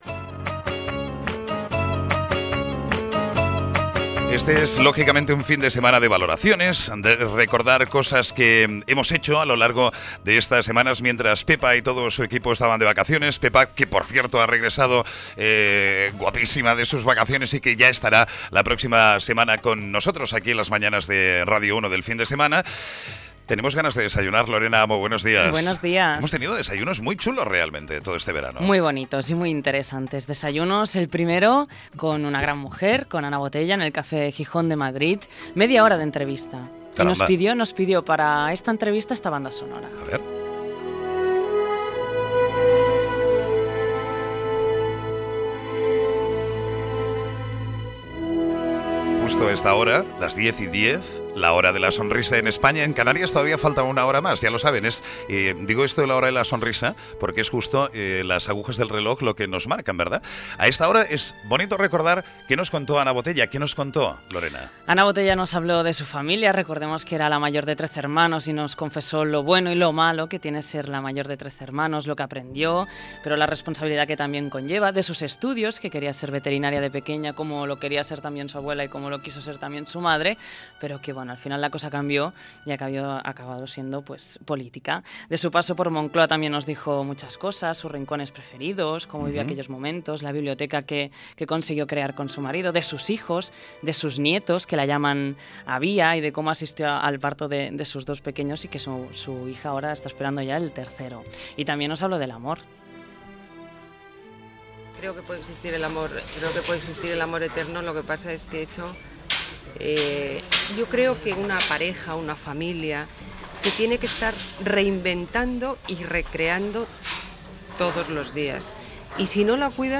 Secció "Desayuno con diamantes", amb un repàs a algunes de les persones que s'han entrevistat durant l'estiu: Ana Botella, Ferran Adrià, Santiago Carrillo i Rafael Amargo Gènere radiofònic Entreteniment